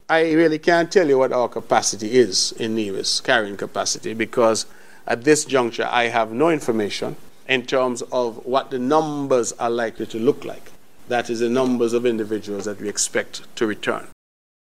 The VONNEWSLINE posed that question to Premier, the Hon. Mark Brantley. This was his response in late January.